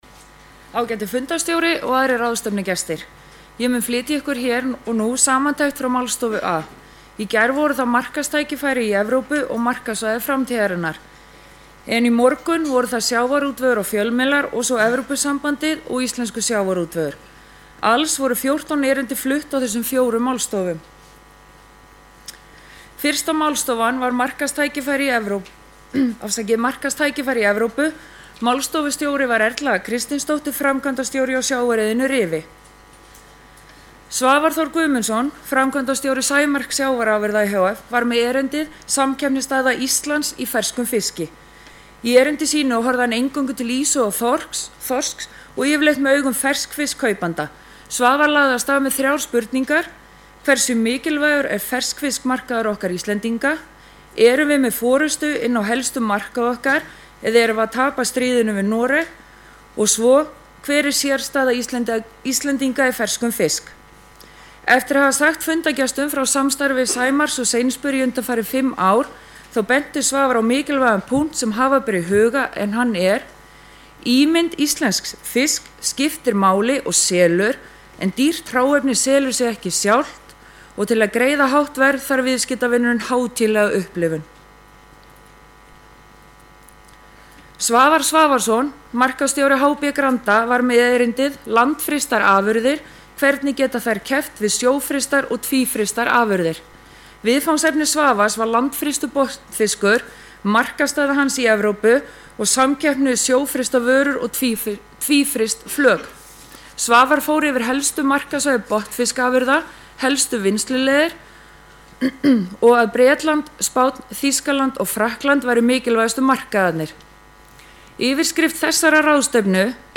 Grand Hótel, 13.-14. október 2011